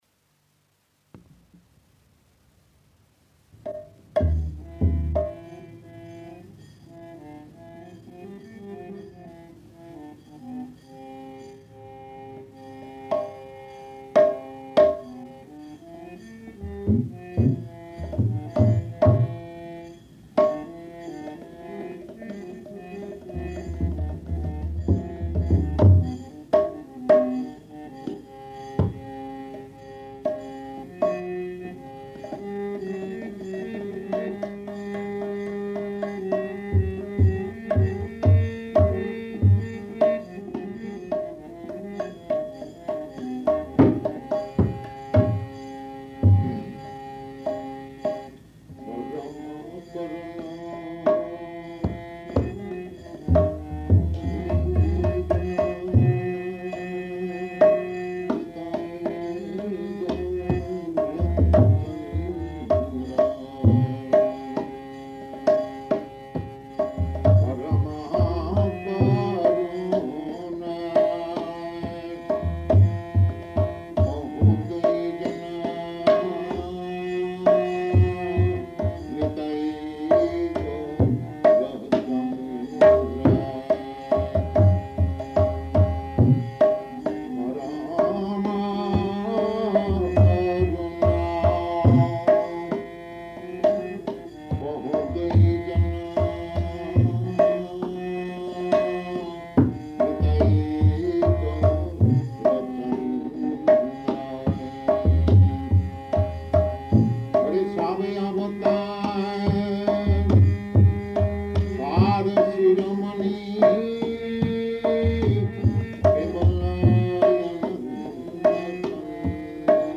Bhajan & Purport to Parama Koruṇa
Type: Purport
Location: Los Angeles